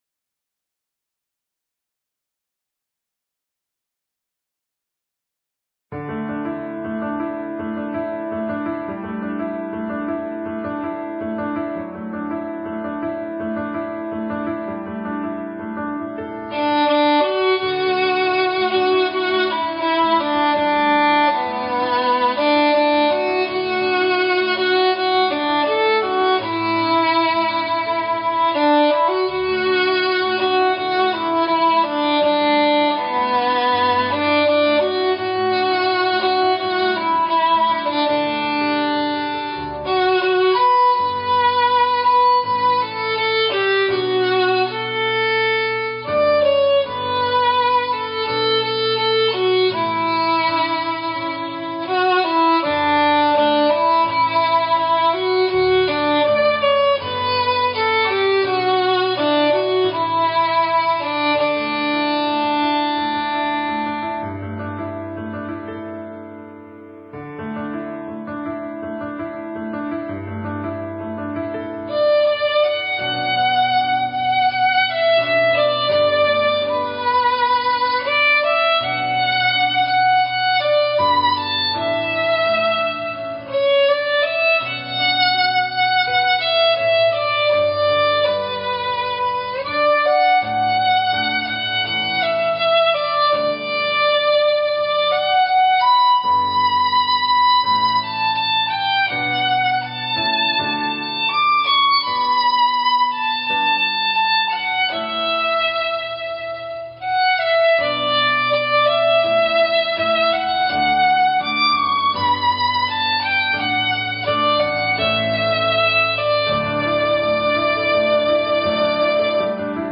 Instrumentation: Violin and Piano
violin solo